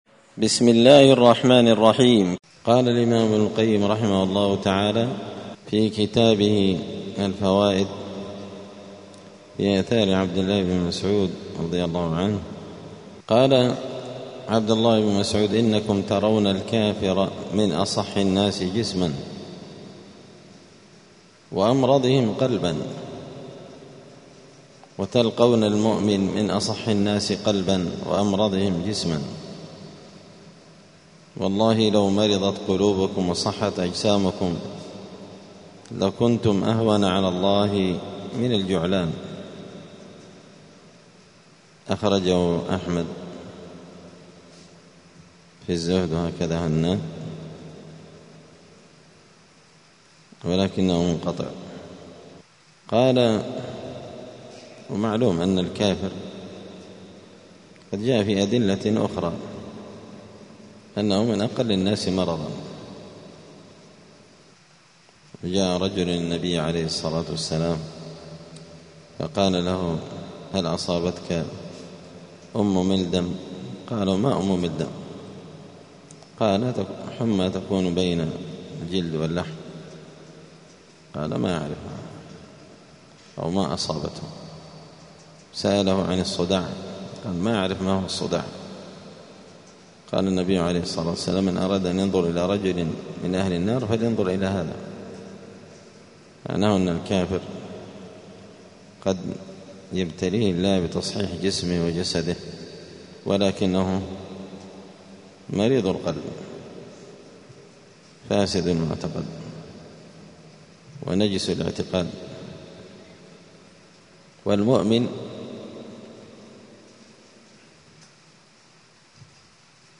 الأحد 2 صفر 1447 هــــ | الدروس، دروس الآداب، كتاب الفوائد للإمام ابن القيم رحمه الله | شارك بتعليقك | 6 المشاهدات
دار الحديث السلفية بمسجد الفرقان قشن المهرة اليمن